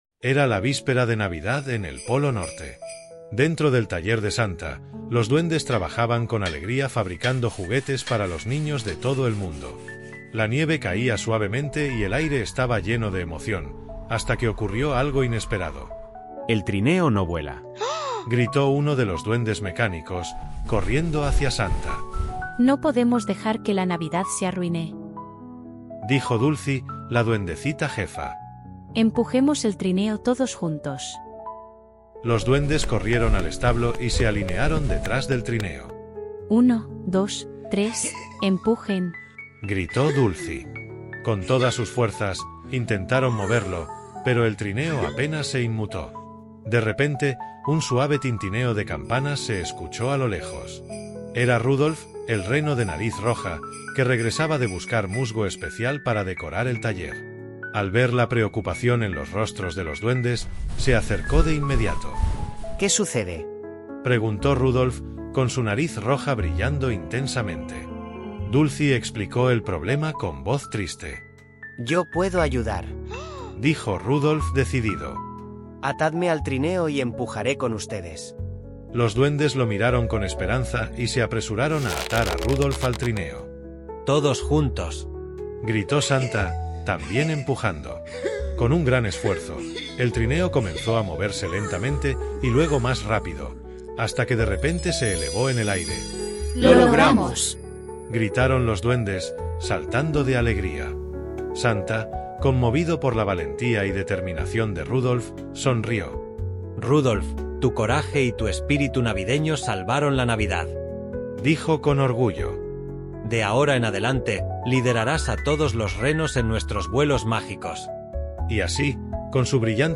T24012-AUDIOLIBRO.mp3